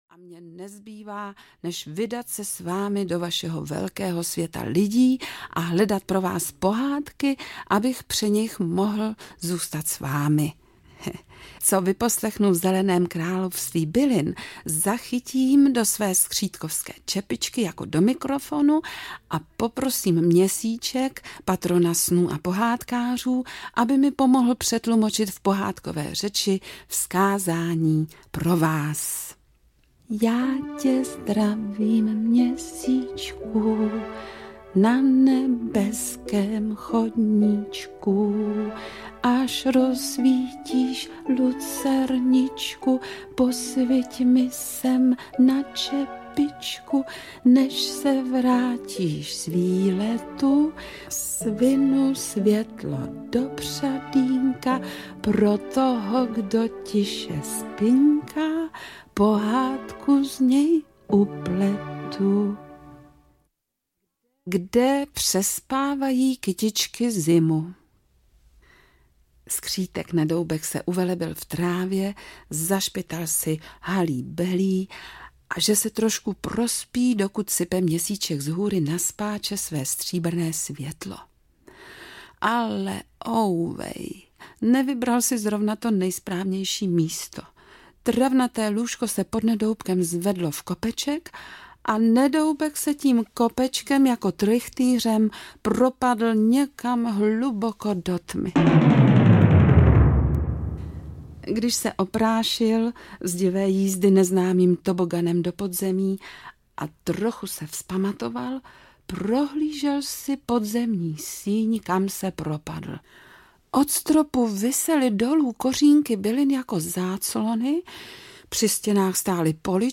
nova-dobrodruzstvi-skritka-nedoubka-audiokniha